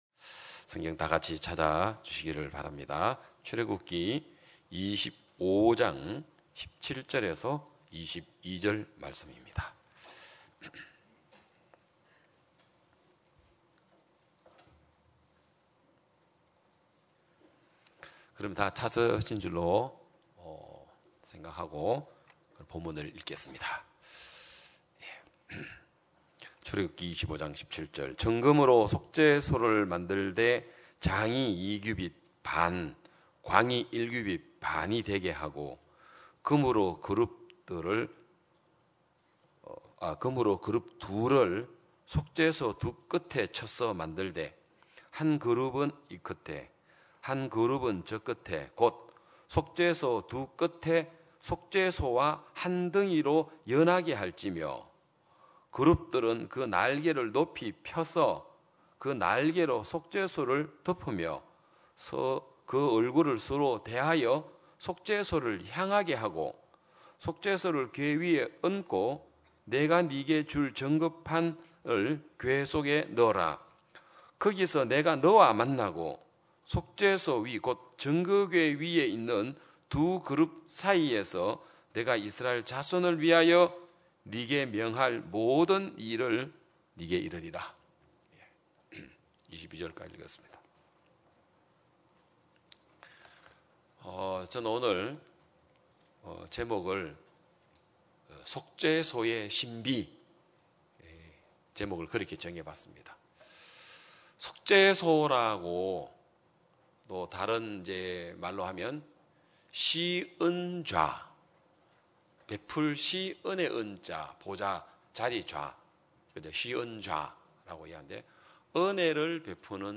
2022년 5월 29일 기쁜소식양천교회 주일오전예배
성도들이 모두 교회에 모여 말씀을 듣는 주일 예배의 설교는, 한 주간 우리 마음을 채웠던 생각을 내려두고 하나님의 말씀으로 가득 채우는 시간입니다.